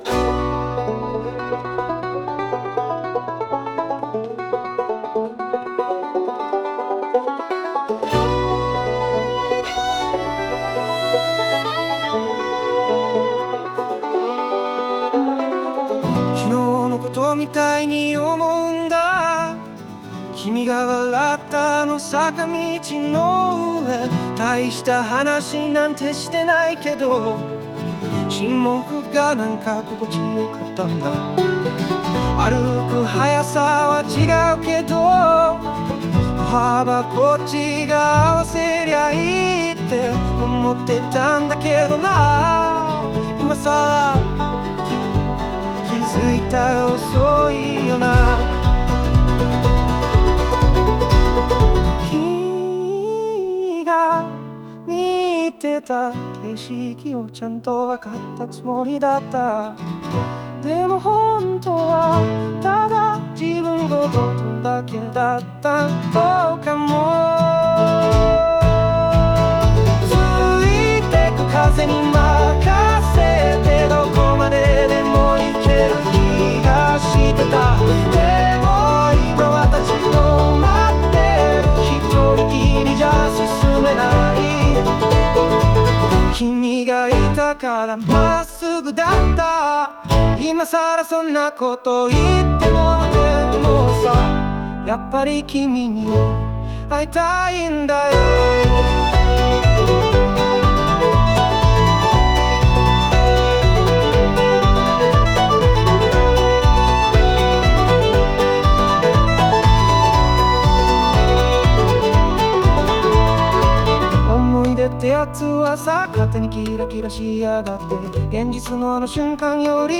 オリジナル曲♪
軽やかなメロディと裏腹に、感情の深さが滲む一曲。